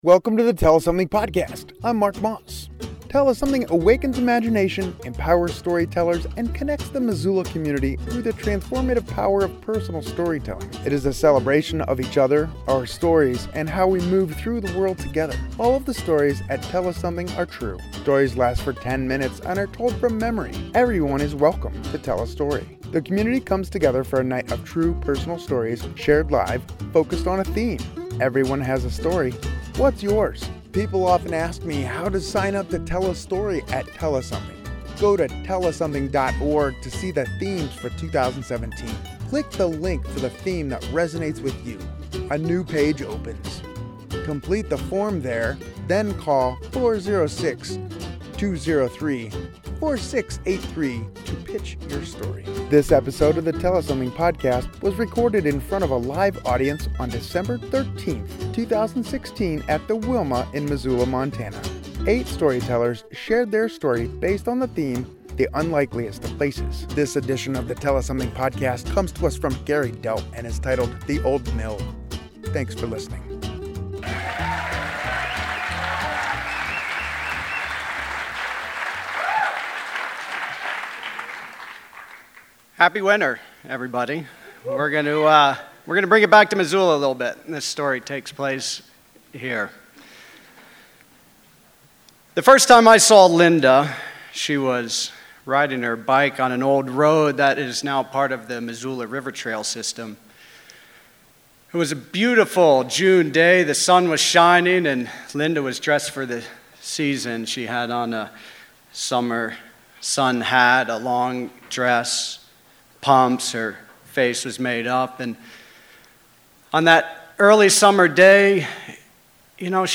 This episode of Tell Us Something was recorded in front of a live audience on December 13, 2016, at The Wilma in Missoula, MT. 8 storytellers shared their story. The theme was “The Unlikliest of Places”.